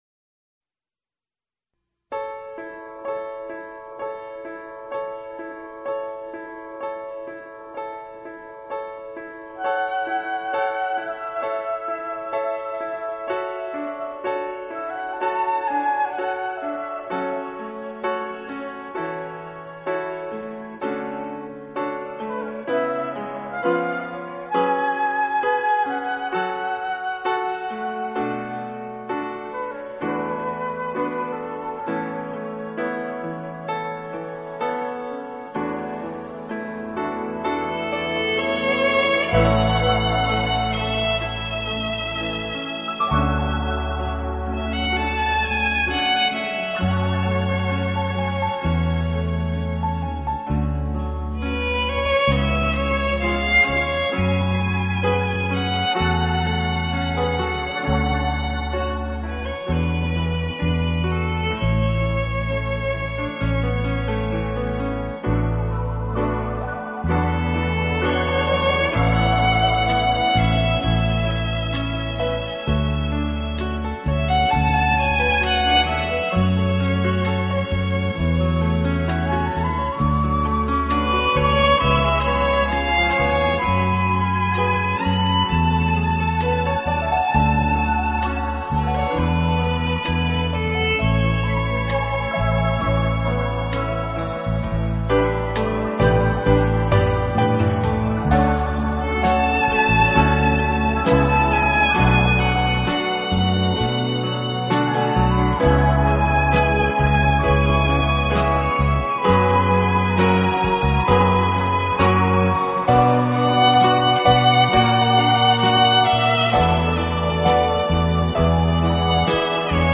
Genre ...........: Instrumental
以小提琴为主旋律，其他音乐为辅。